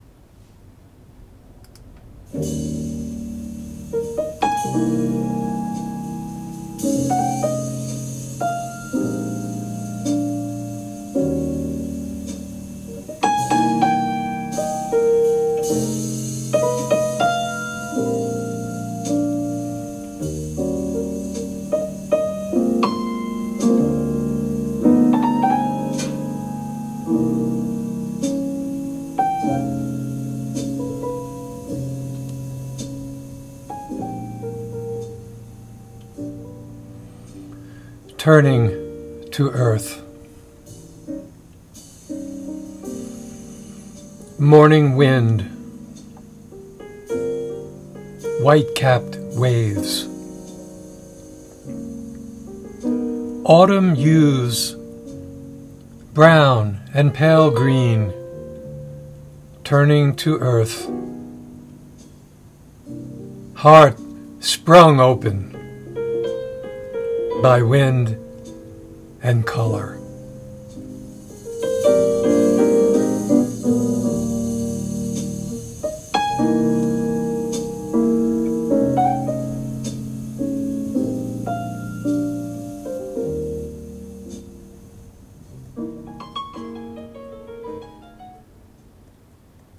Reading of “Turning to Earth” with music by the Bill Evans Trio.